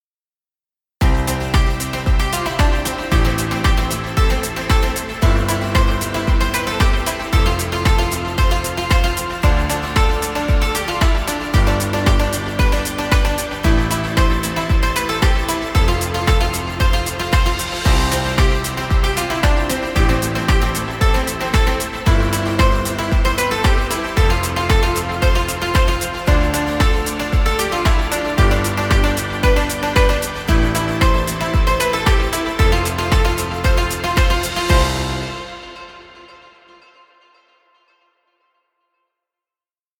Positive happy music. Background music Royalty Free.